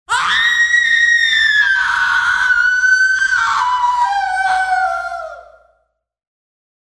Звуки криков